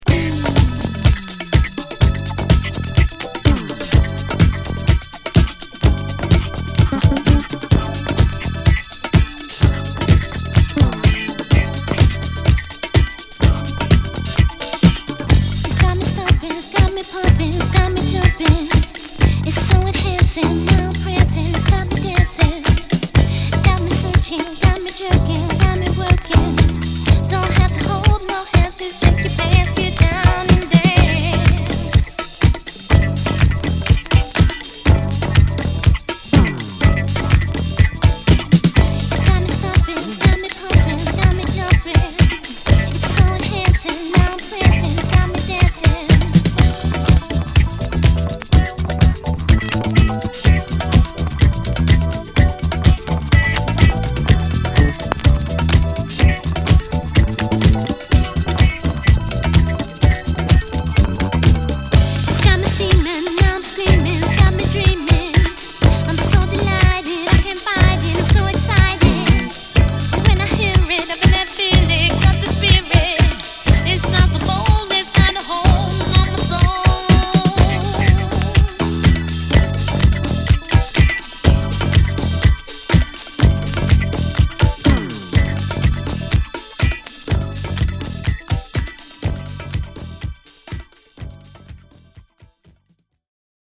Disco~Garage Classic!!